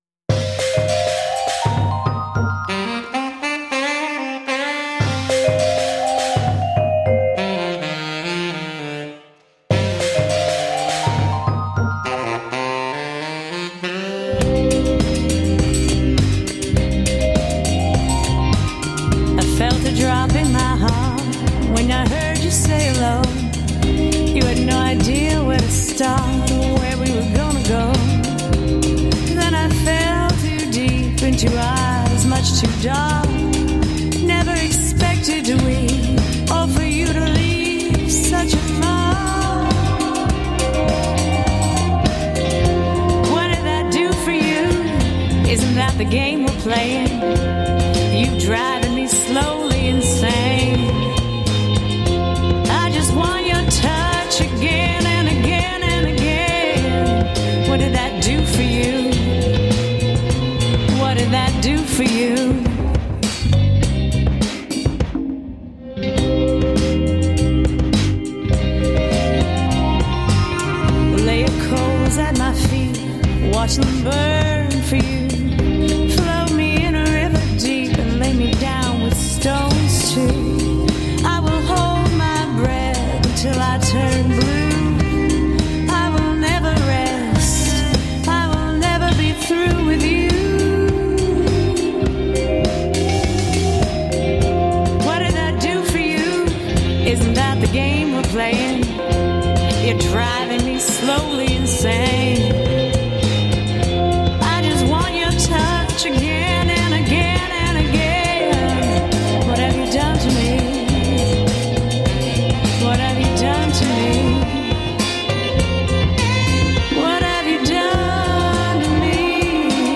Beat-heavy, darkened mix of r'n'b and soul.
Tagged as: Alt Rock, Other